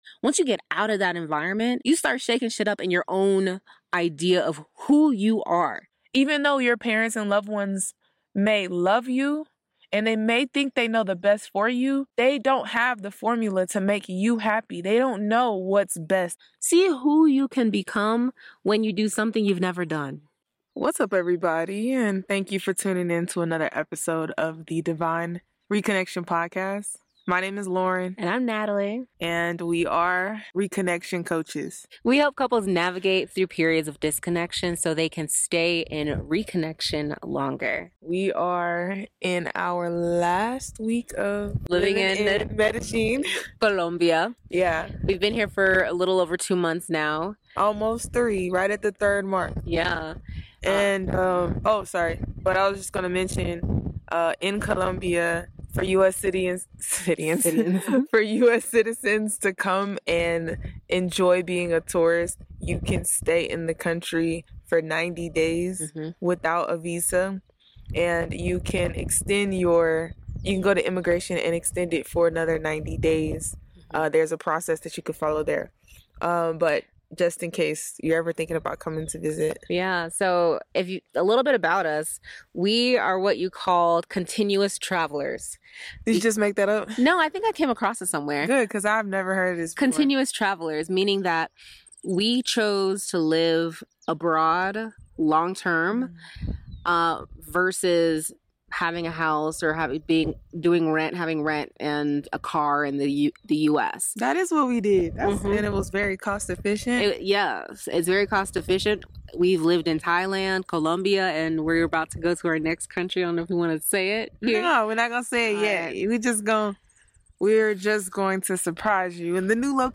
In this episode, we're broadcasting from the beautiful Medellín, Colombia, wrapping up our incredible three-month stay. We delve into our lives as continuous travelers—embracing the freedom of living abroad long-term, sharing our experiences, insights, and the invaluable lessons we've learned along the way.